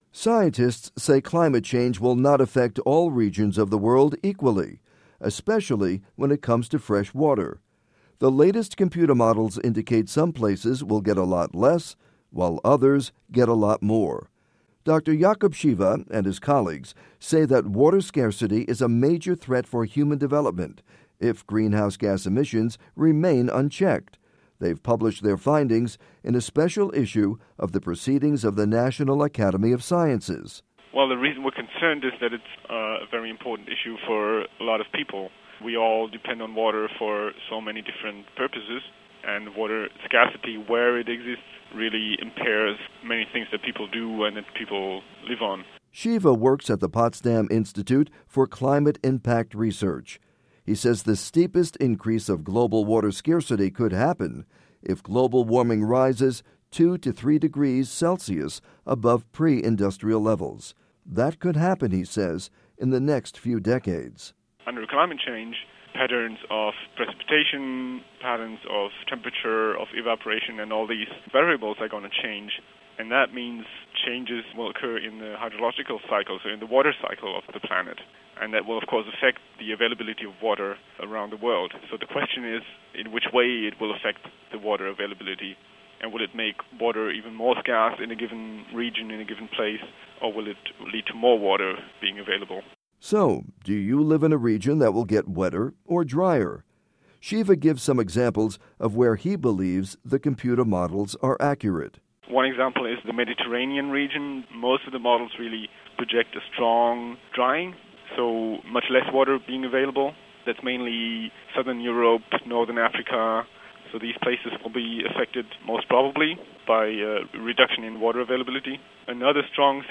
report on climate and water